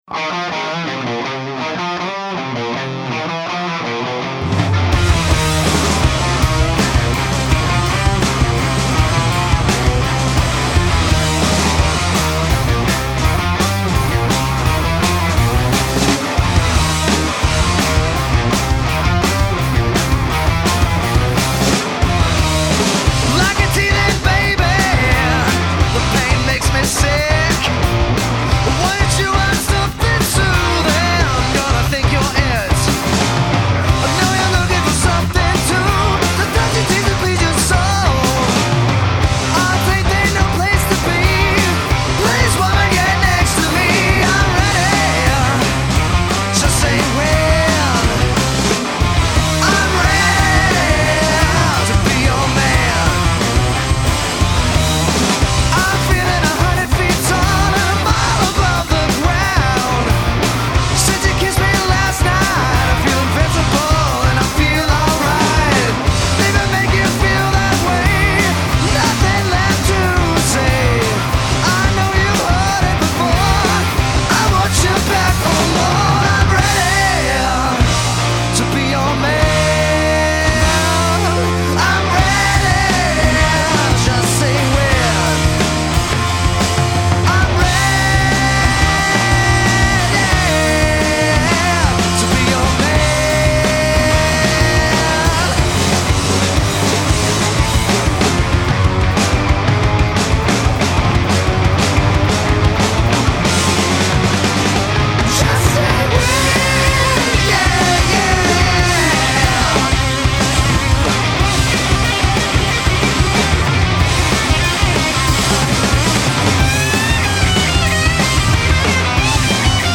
The garage band’s